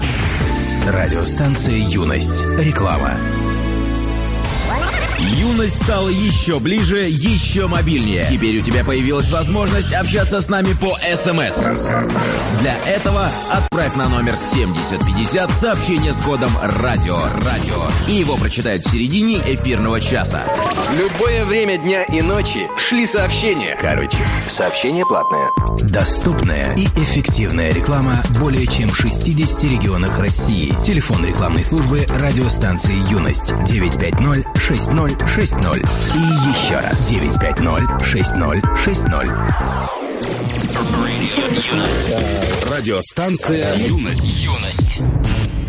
Архив Радио Юность Оформление
Рекламный блок (Радио Юность, 09.12.2006)